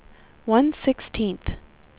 speech / tts / prompts / voices
number_3.wav